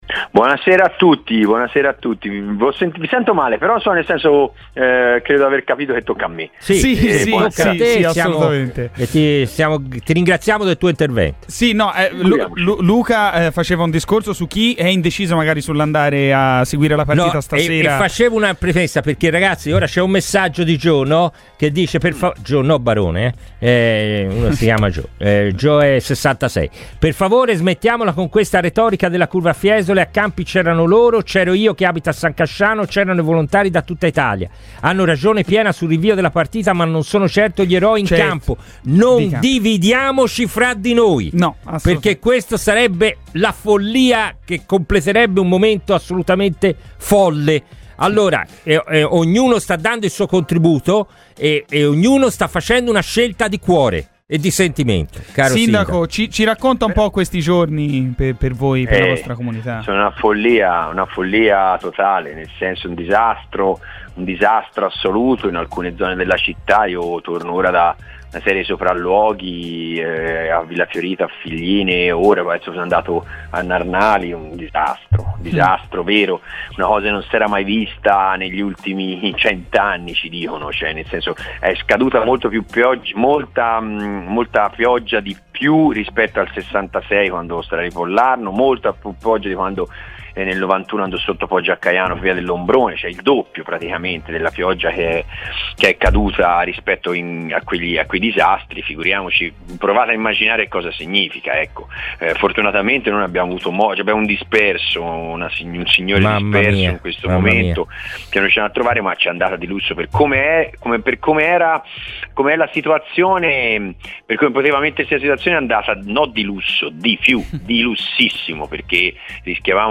Matteo Biffoni, sindaco di Prato, è intervenuto su Radio FirenzeViola per commentare la difficile situazione che stanno vivendo i cittadini pratesi e non solo, dopo le violenti piogge in Toscana: “Sono giorni folli.